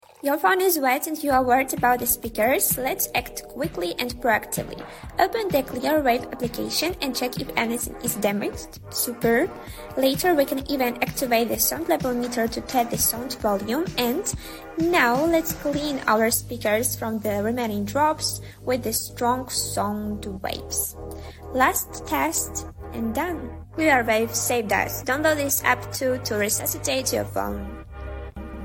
📱💦 Remove Water from Phone sound effects free download